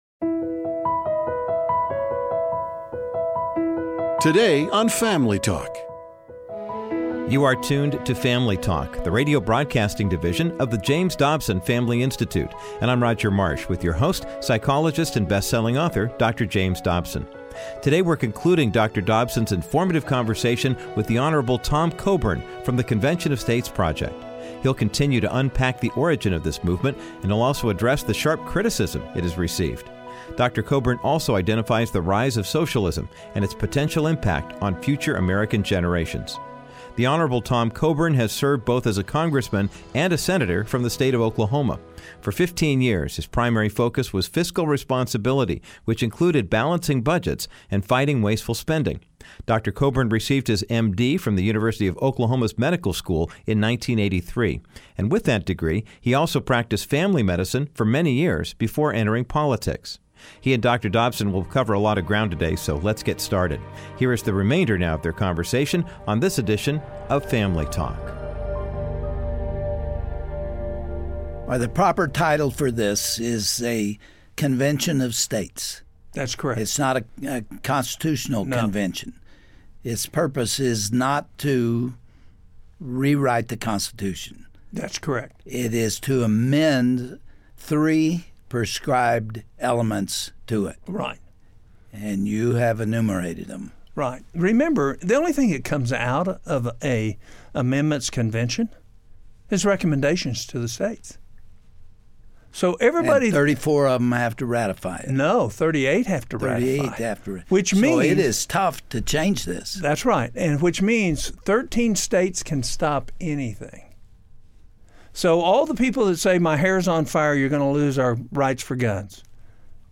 Dr. Dobson continues his discussion about the Convention of States project with former Senator Tom Coburn. They highlight the key issues this movement could solve, and share their concerns about the rise of socialism in America.